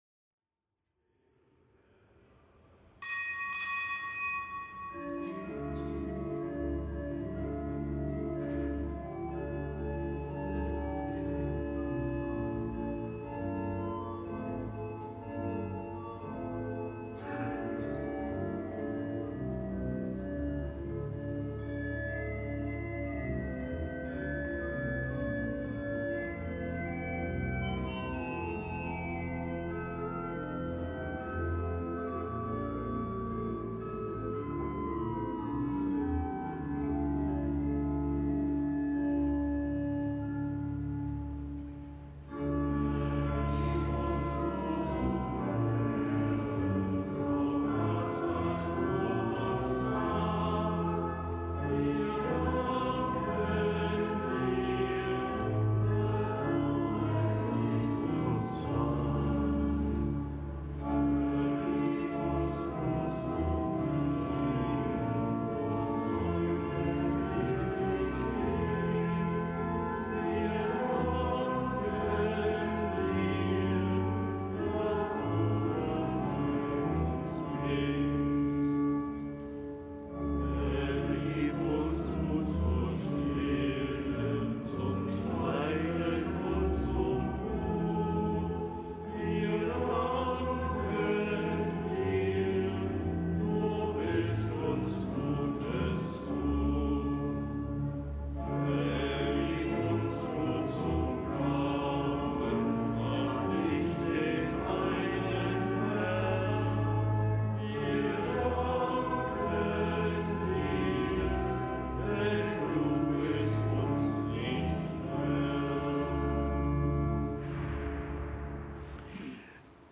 Kapitelsmesse aus dem Kölner Dom am Dienstag der zweiten Fastenwoche, Nichtgebotener Gedenktag Heiliger Cyrill von Jerusalem, Bischof, Kirchenlehrer. Zelebrant: Weihbischof Rolf Steinhäuser.